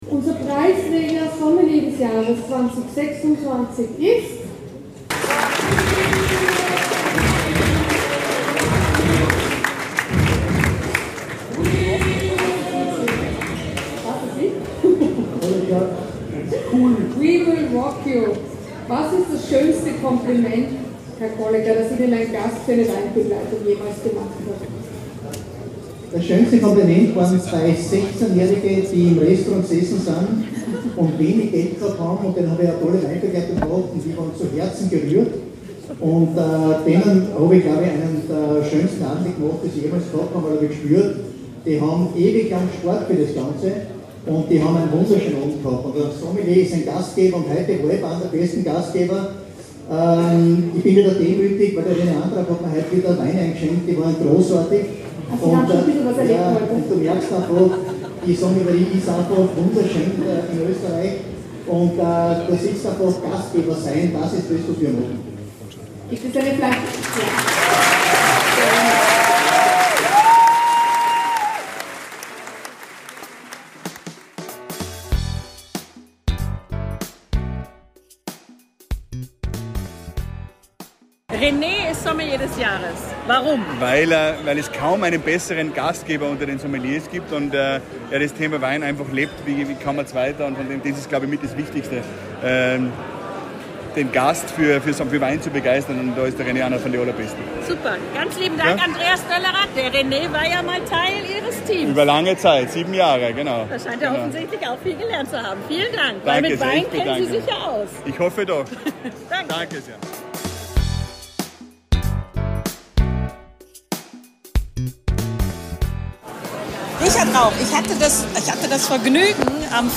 O-Töne und anschließend gewohntes Fachsimpeln mit ganz viel persönlicher Note.